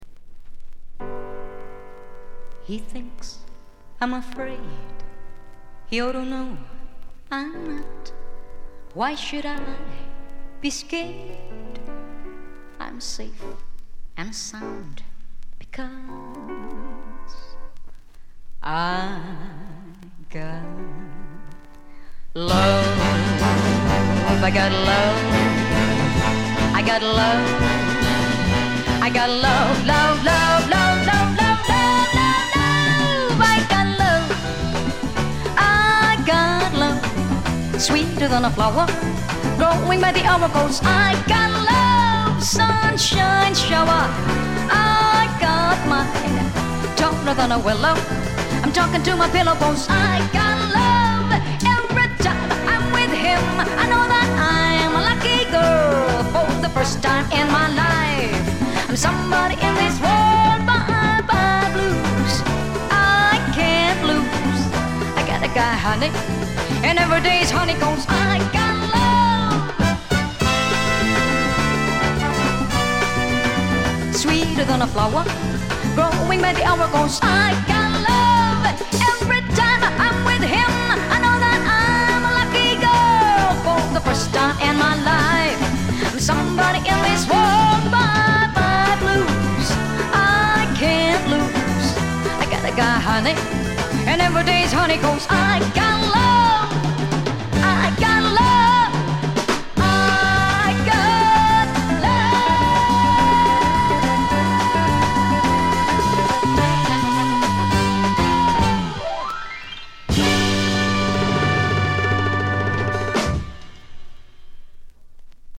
フィリピン出身の女性シンガー
ライブ録音らしくバンドと一体感のあるグルーヴが素晴らしいです。
使用感という意味では新品同様極美品ですが、自主盤らしいプレス起因と思われる軽微なチリプチが少し聴かれます。
試聴曲は現品からの取り込み音源です。